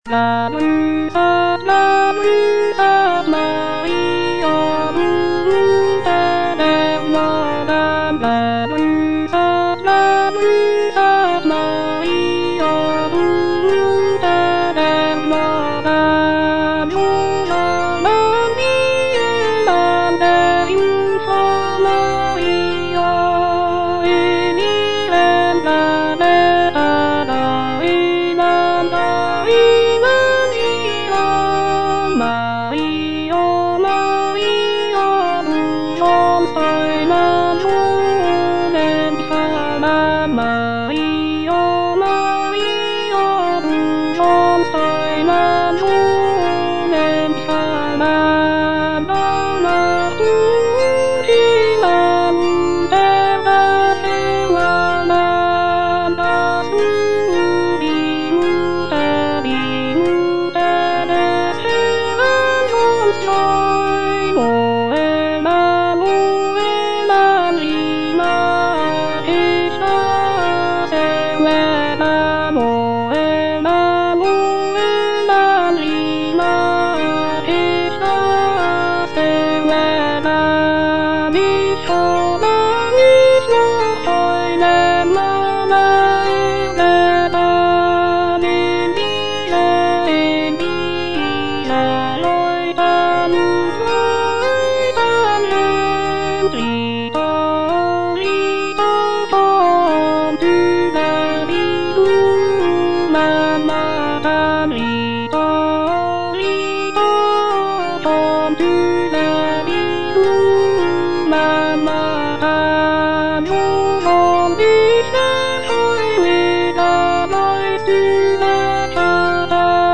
choral composition